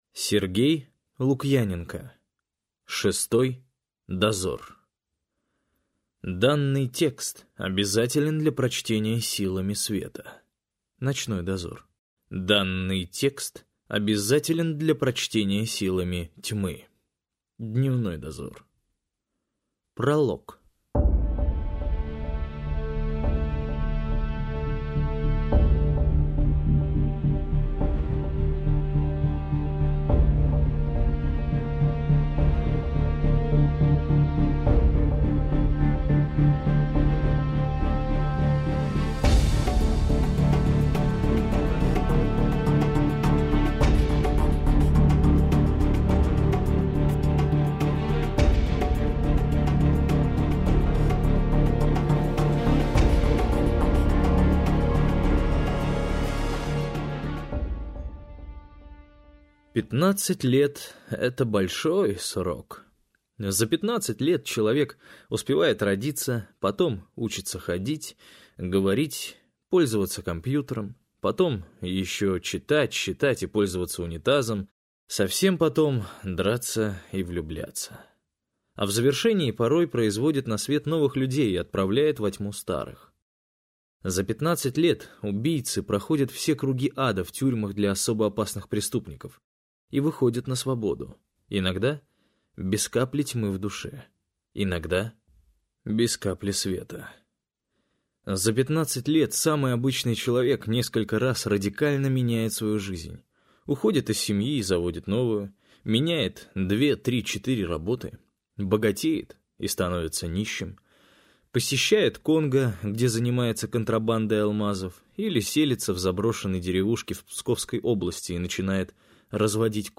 Аудиокнига Шестой Дозор - купить, скачать и слушать онлайн | КнигоПоиск